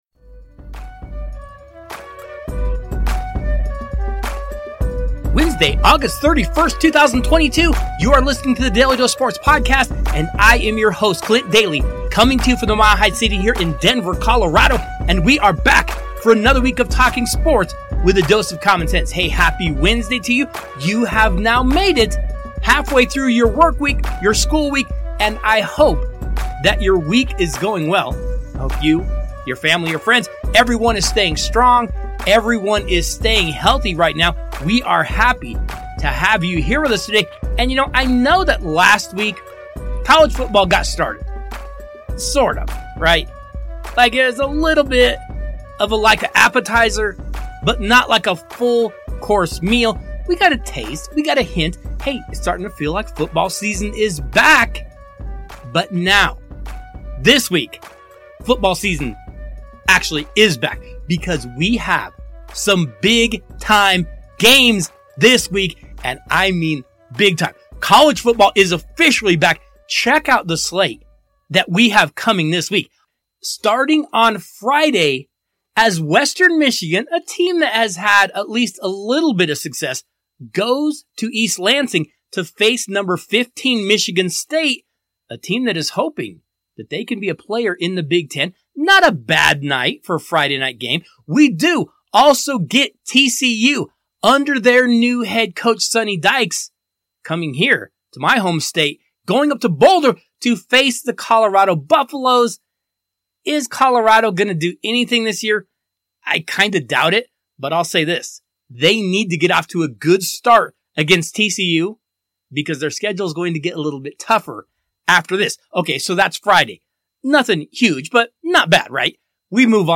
This week on the Daly Dose, we are joined by three friends of the show to preview the 2022 NFL season!